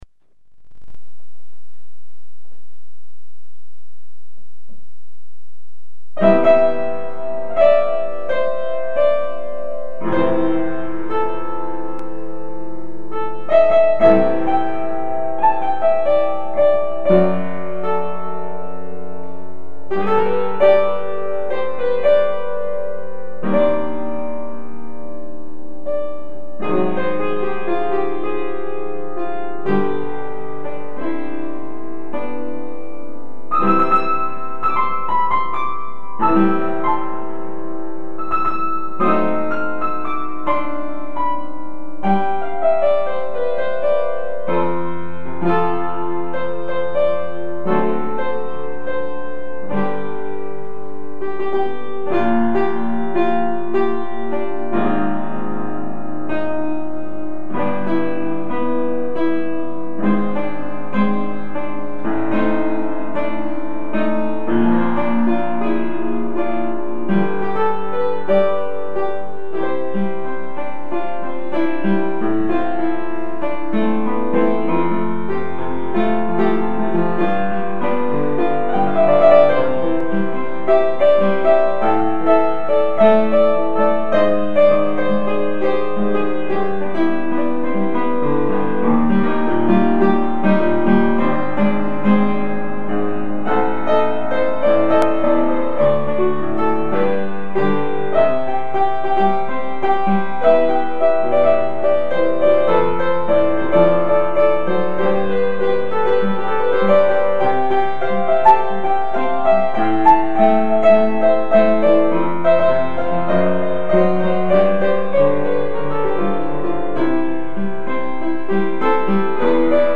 פשוט נתתי לאצבעות לזרום על הקלידים.. אפשר לקרוא לזה אלתור חופשי.
נעים וזורם כפי שציינת